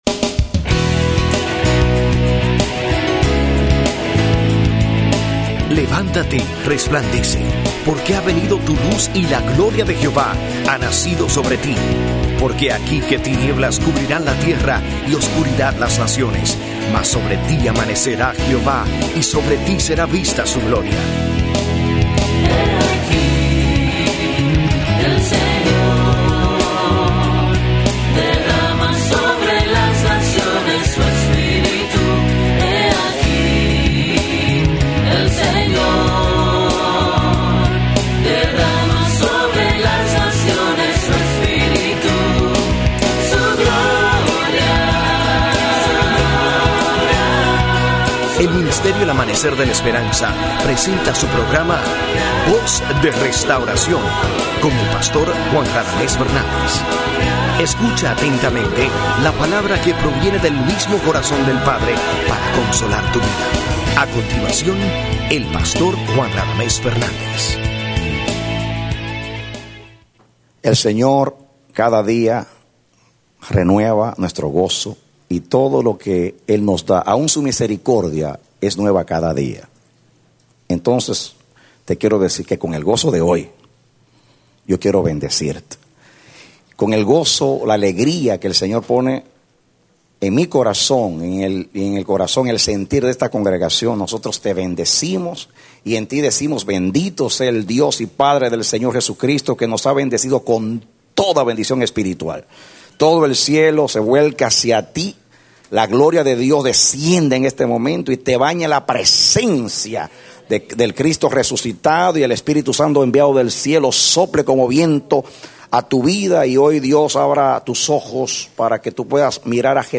Predicado Noviembre 2, 2008